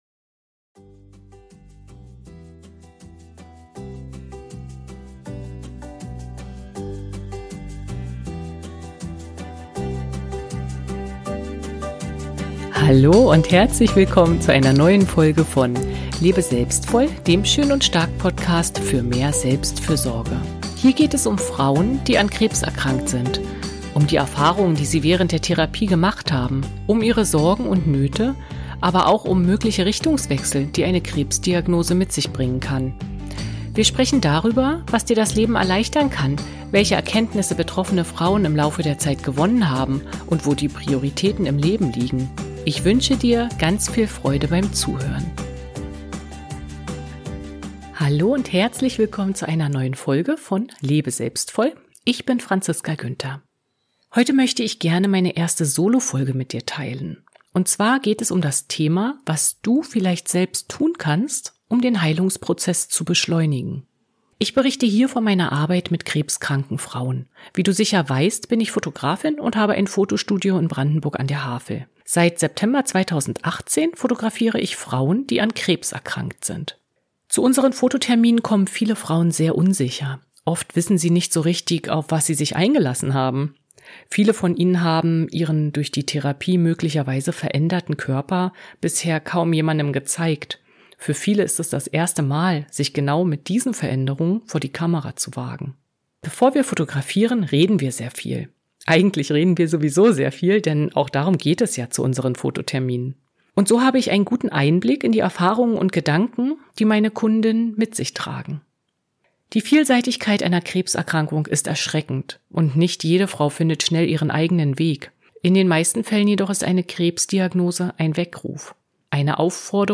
Beschreibung vor 2 Jahren Heute möchte ich gerne meine erste Solofolge mit dir teilen. Und zwar geht es um das Thema, was du vielleicht selbst tun kannst, um den Heilungsprozess zu beschleunigen.